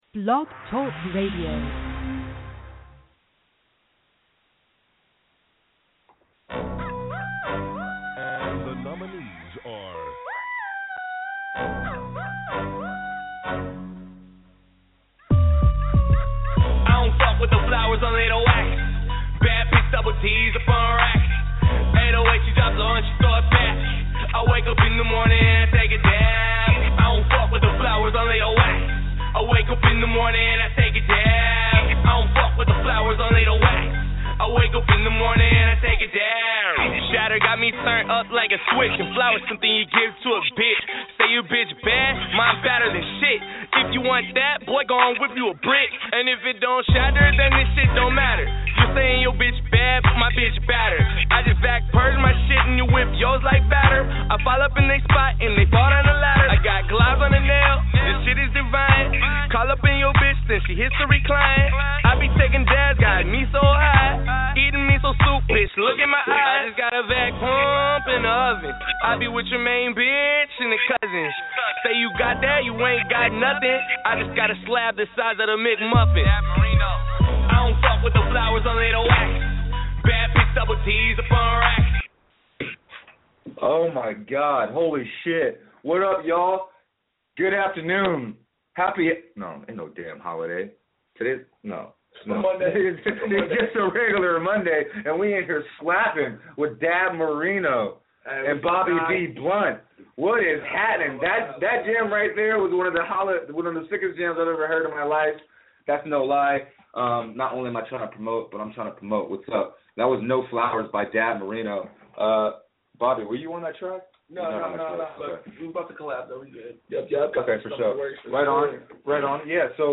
Call in or just listen to rants and discussions about all that's happening in most forms of media such as entertainment, pop culture, sports, politics, economics and even pro wrestling and MMA!